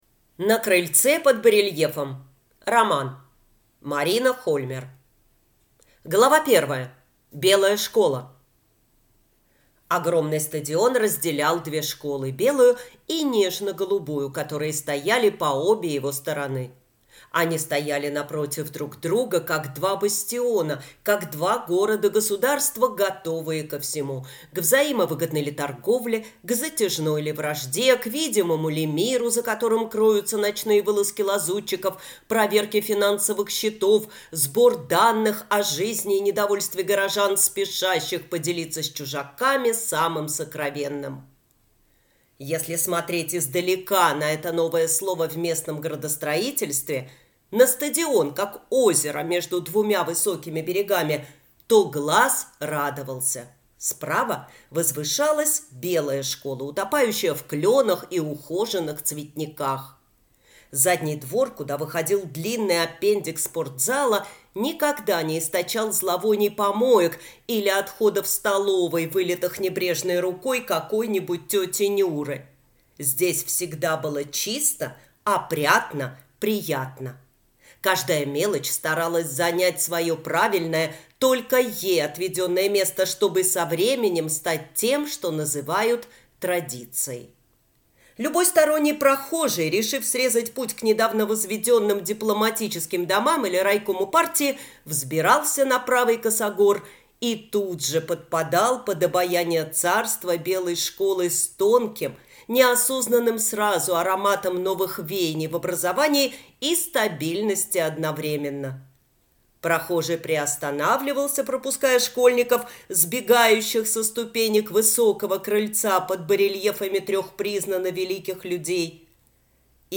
Аудиокнига На крыльце под барельефом | Библиотека аудиокниг